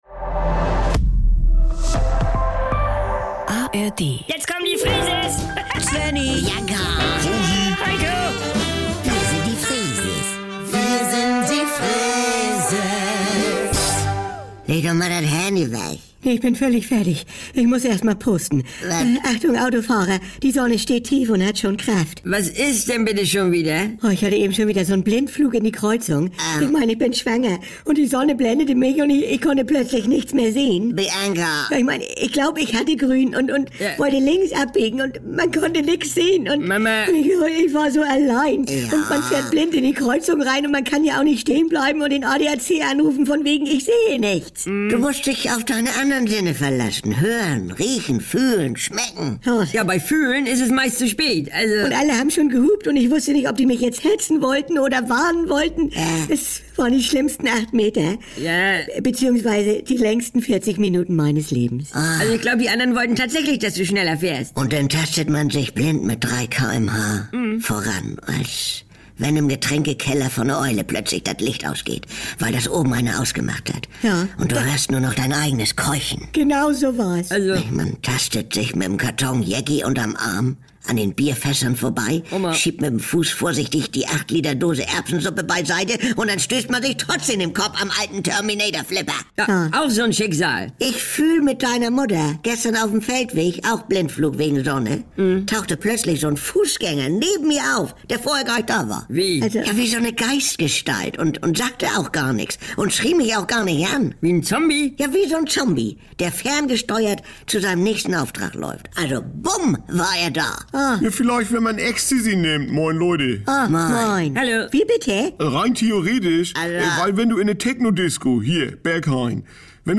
… continue reading 1832 episode # Saubere Komödien # NDR 2 # Komödie # Unterhaltung